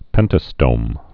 (pĕntə-stōm)